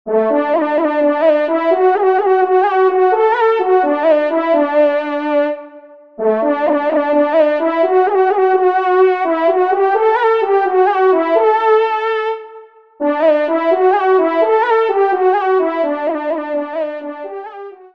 1e Trompe  (Ton de vénerie)